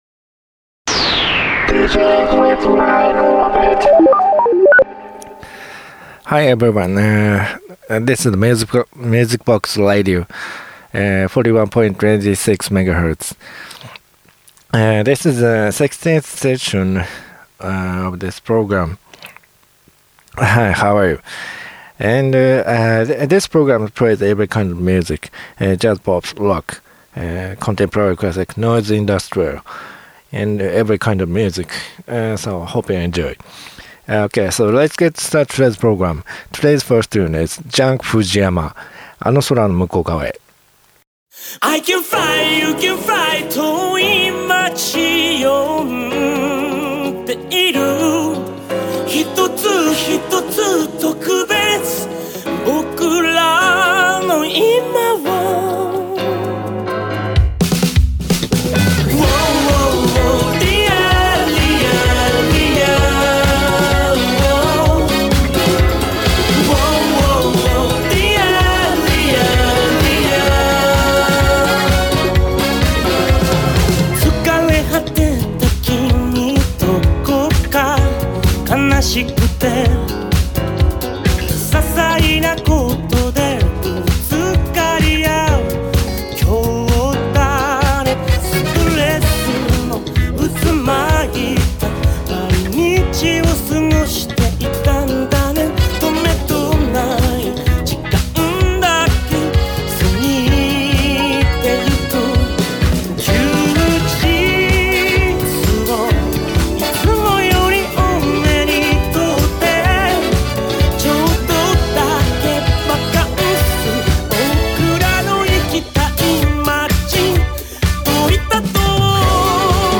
Japanese Pops(1980-2014)